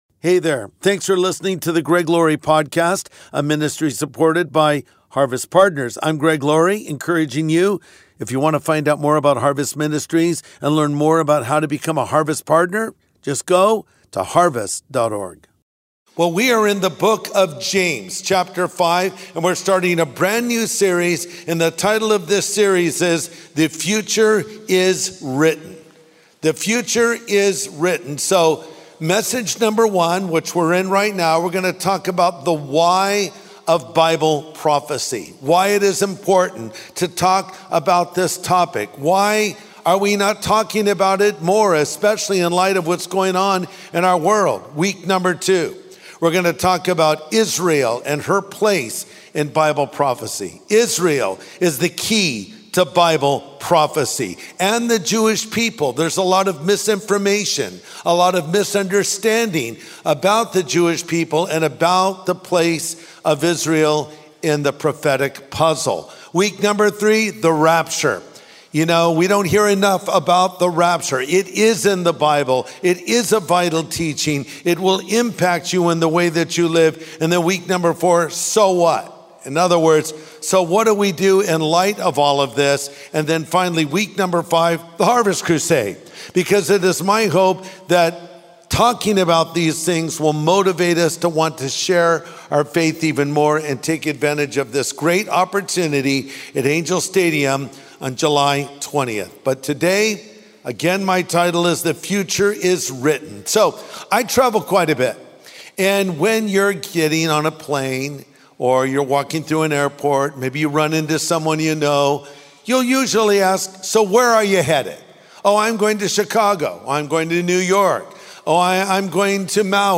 The Future Is Written | Sunday Message Podcast with Greg Laurie
Bible prophecy is given not to scare us, but to prepare us. In this message, Pastor Greg Laurie reminds us that Jesus is coming back again and we should respond to that fact accordingly.